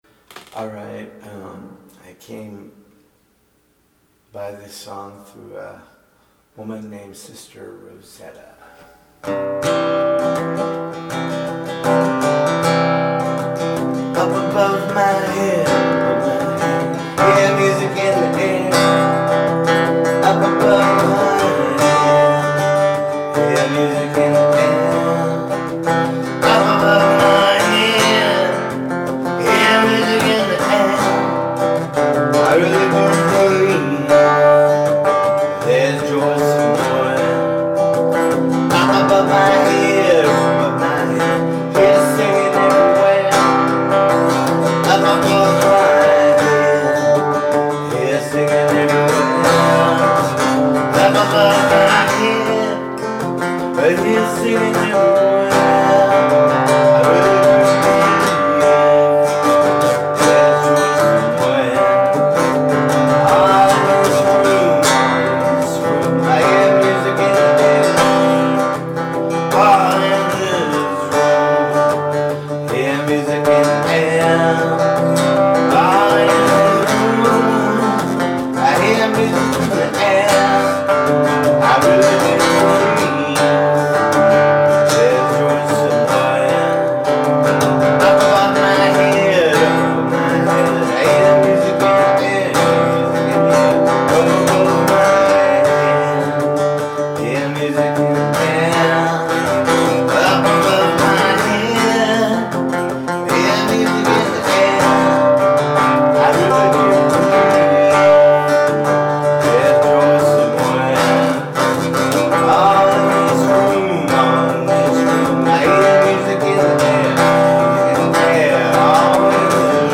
Up Above My Head, live